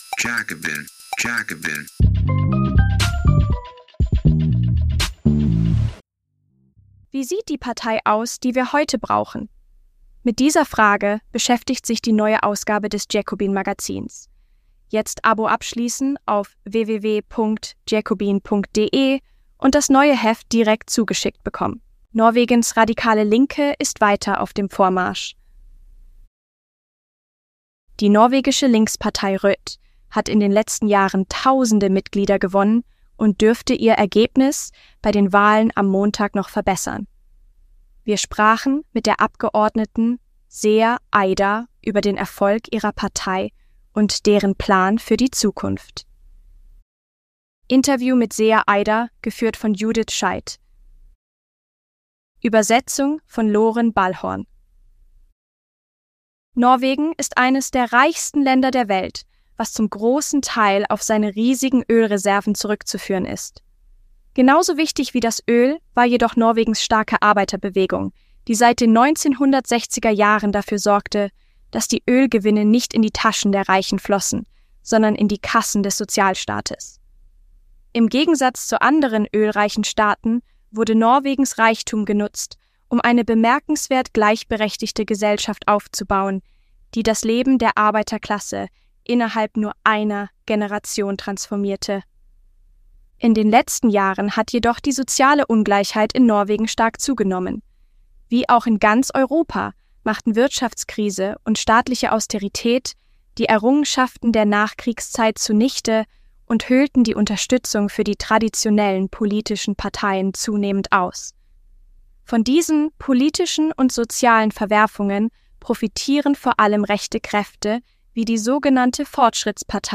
Norwegens radikale Linke ist weiter auf dem Vormarsch – Interview mit Seher Aydar